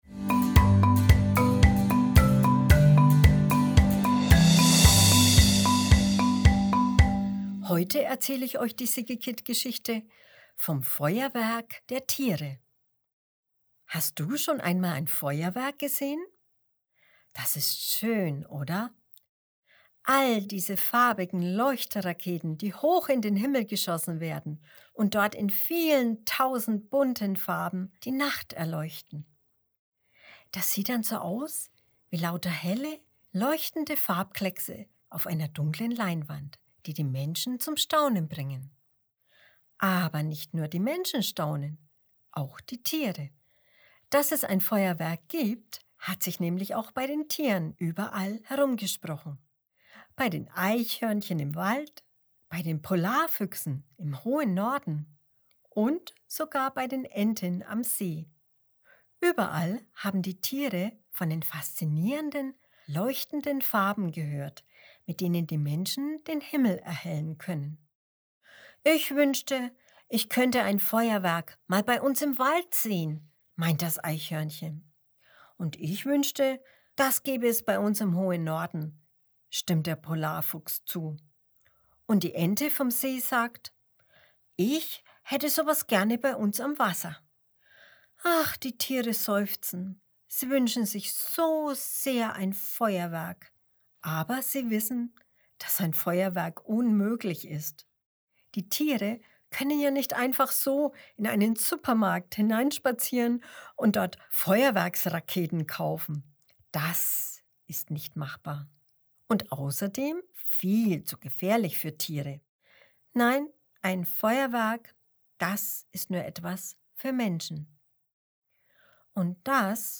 Dezember 2021 Kinderblog Jahreszeiten, Winter, Vorlesegeschichten Hast du schon einmal ein Feuerwerk gesehen?